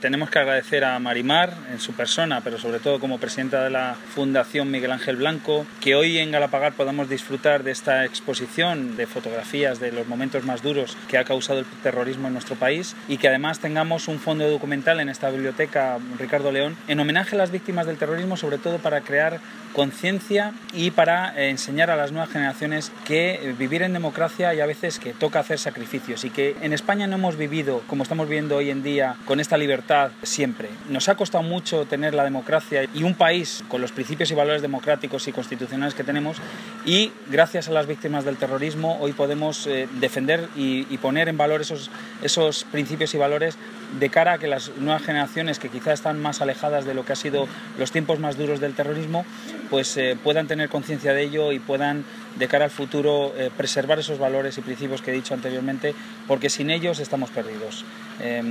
Intervencion_del_alcalde_Daniel_Perez_Munoz.mp3